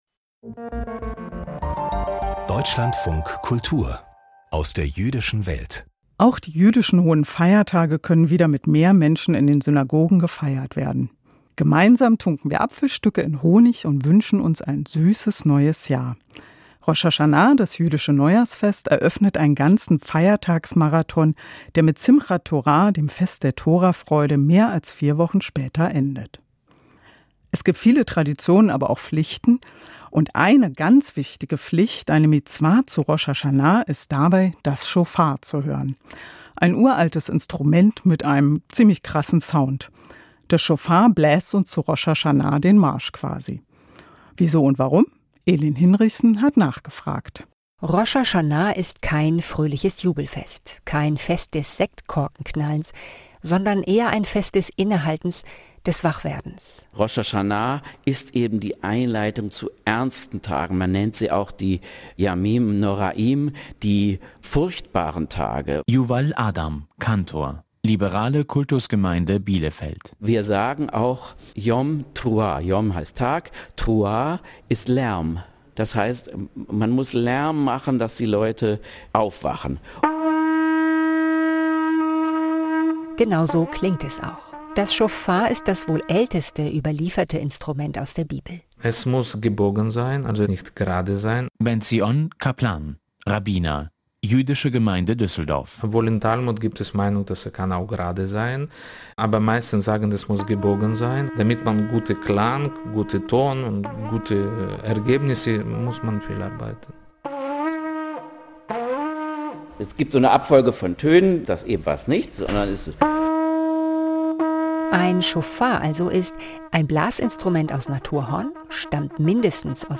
Ein ganz wichtiges Gebot zu Rosch Haschana, dem jüdischen Neujahr, ist es das Schofar zu hören; ein uraltes BlasiInstrument aus Horn. Der Klang ruft zur Selbstreflexion auf, aber auch Rührung hervor.